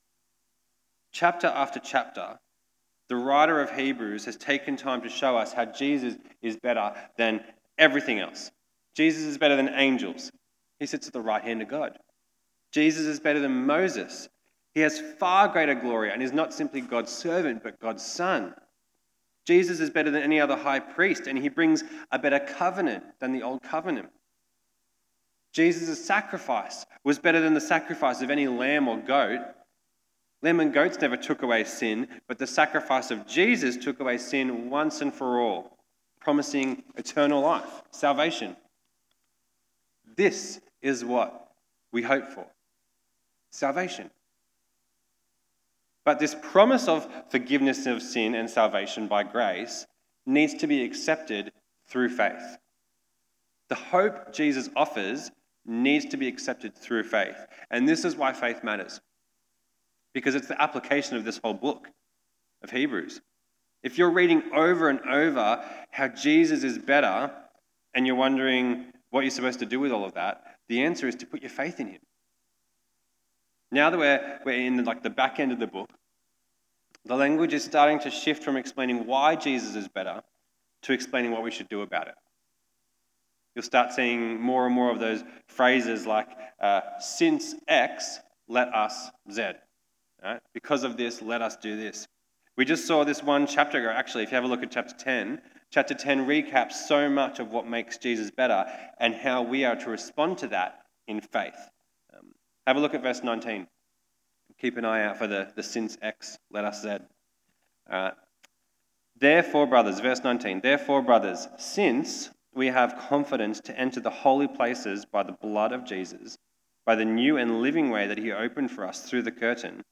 Sermons | The Point Community Church